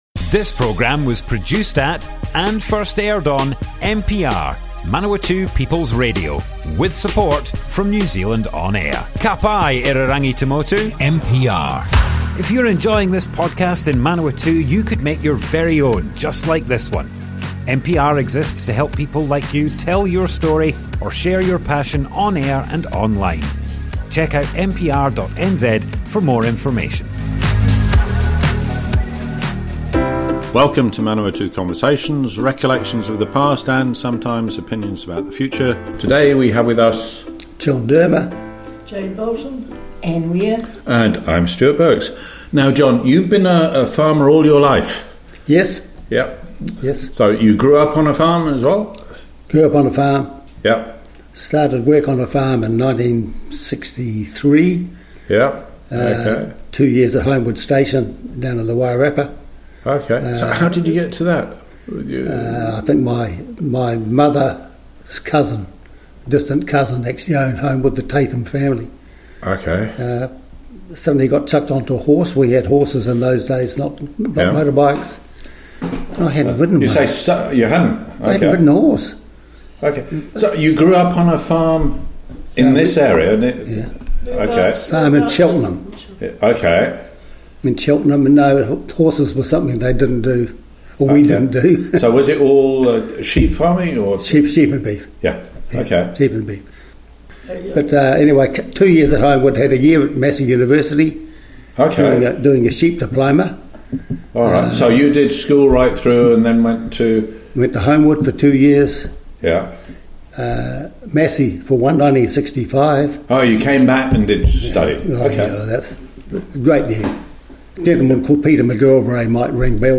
Manawatu Conversations Object type Audio More Info → Description Broadcast on Manawatu People's Radio, 10th February 2026.
oral history